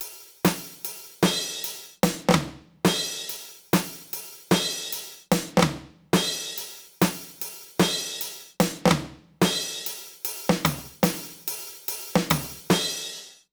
British ROCK Loop 136BPM (NO KICK).wav